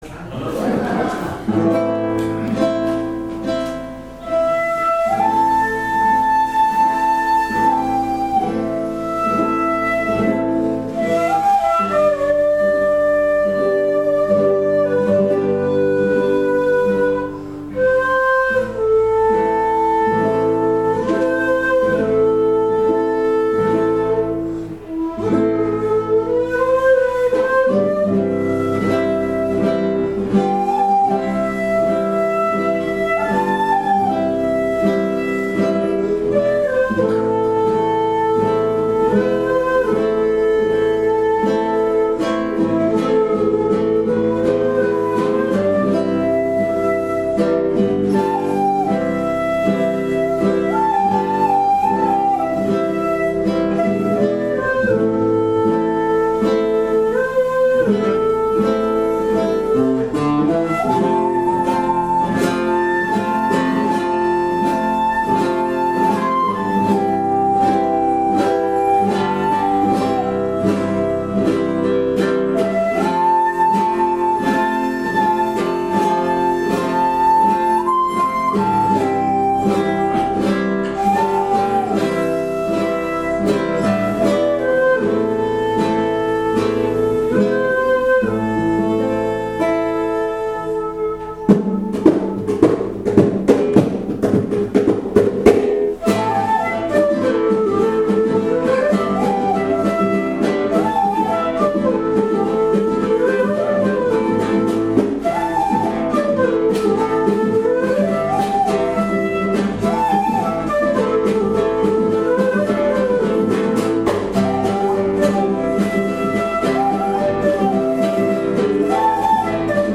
～尺八とｷﾞﾀｰで奏でる～
そして、少しかすれたような音色はルーマニアのパンフルートやアンデスのケーナと共通で人の心を惹きつけて止みません。